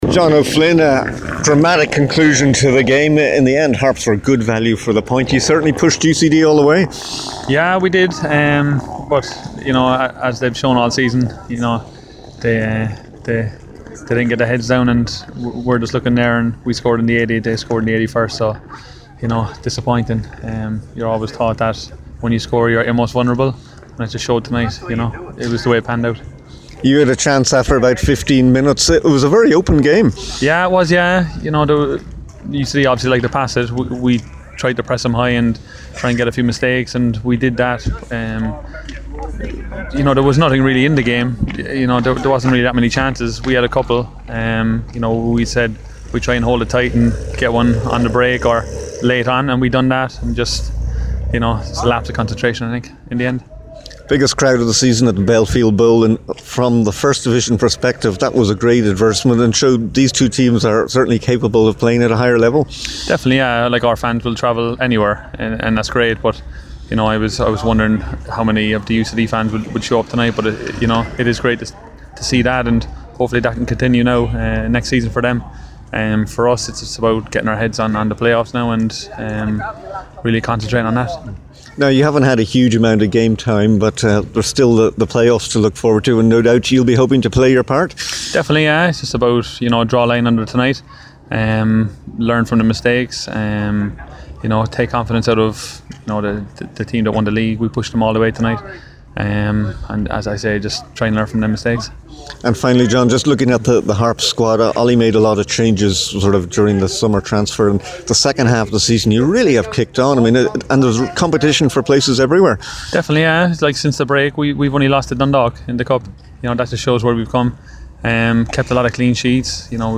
After the match